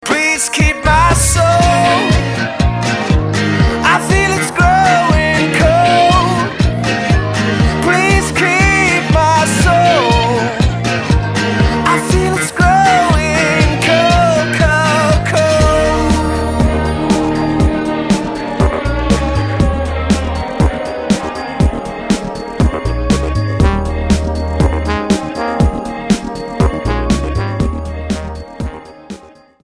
STYLE: Rock
bass
keyboard
vocals